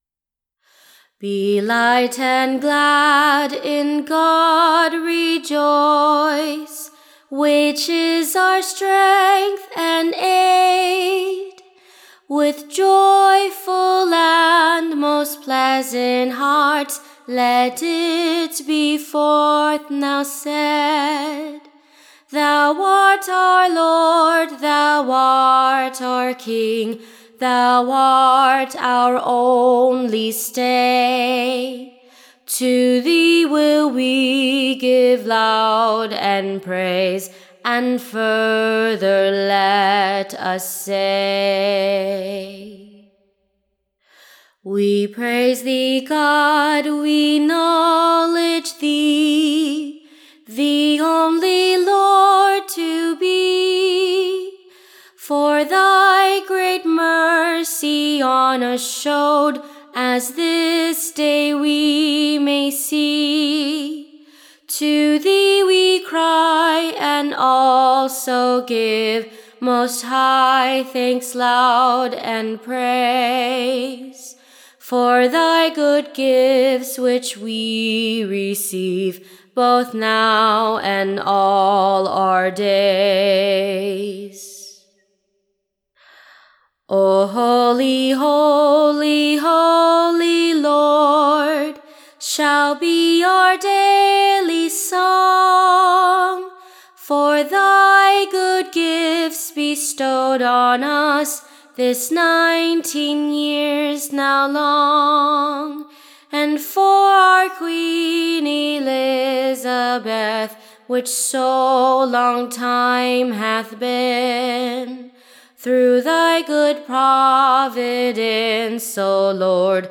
Tune Imprint Sing this as the foure score and one Psalme. Standard Tune Title Psalm 81 Media Listen 00 : 00 | 23 : 18 Download c1.165_Psalm_81.mp3 (Right click, Save As)